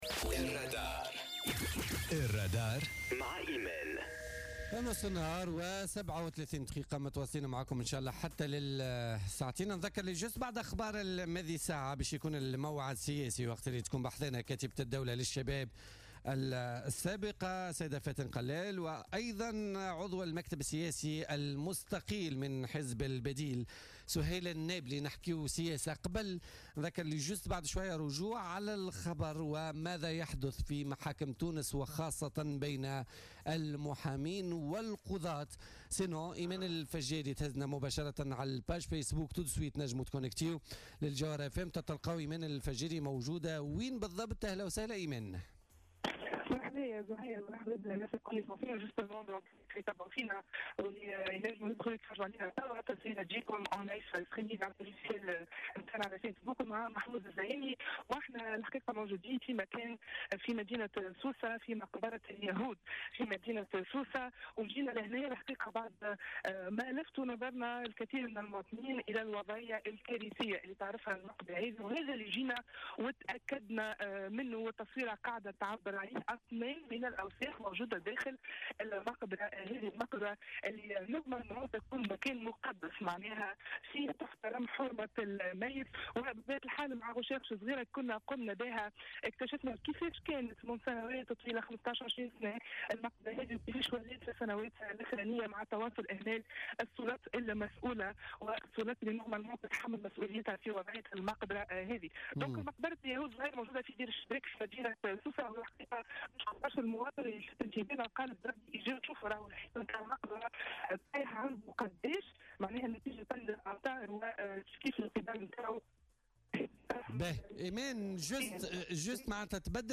تحول فريق "الرادار" اليوم الاثنين 16 اكتوبر 2017، إلى مقبرة اليهود بسوسة، لمعاينة أوضاعها، بعد تلقي عديد التشكيات من المواطنين القاطنين بجانبها.